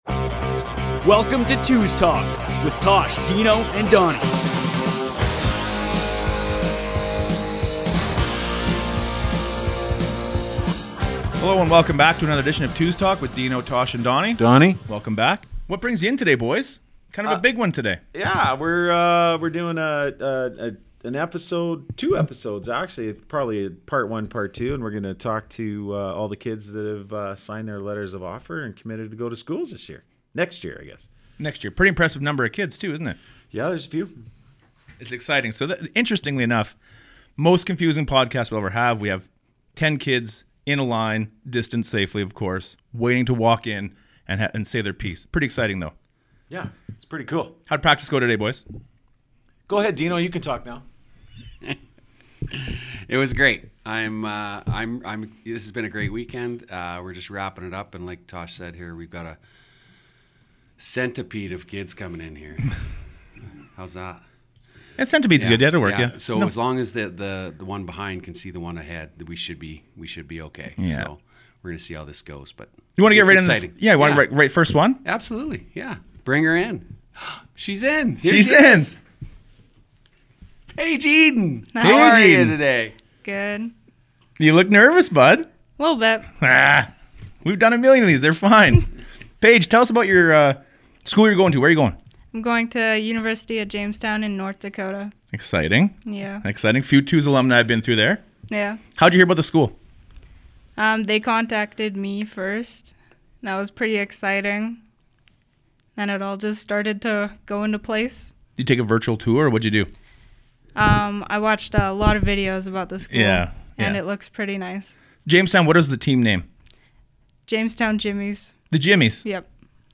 The fellas sit down with members of the 2021 Graduating Class of the 222s Travel Team that are making their way to the US next fall for college. It is such a rewarding feeling to think that some of these players have been in the program from the very beginning and now their softball journey will take them to the next level.